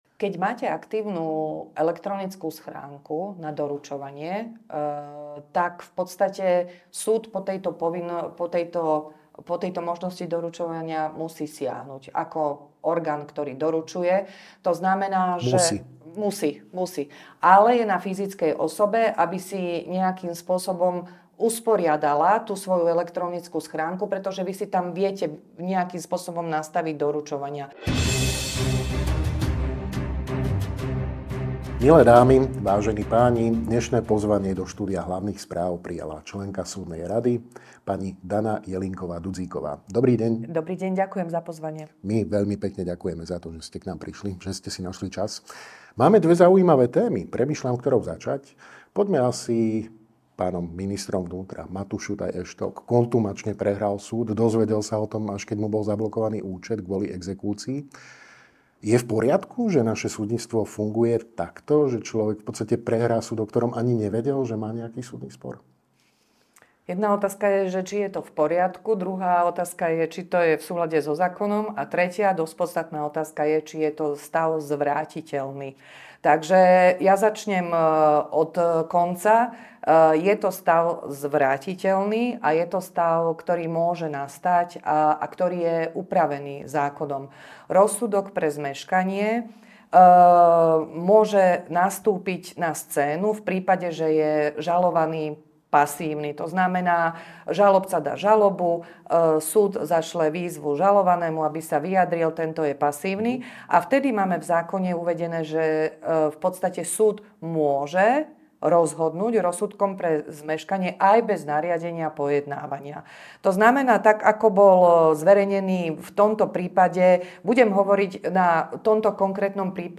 Dozviete sa vo videorozhovore s členkou súdnej rady, JUDr. Danou Jelínkovou Dudzíkovou, LL.M.